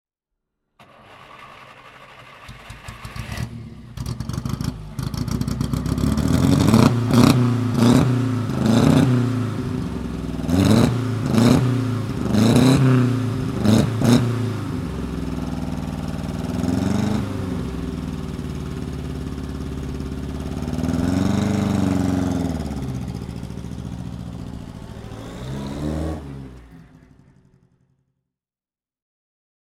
Fiat Balilla 508 CS Coppa d'oro (1934) - Starten und Leerlauf
Fiat_Balilla_1935.mp3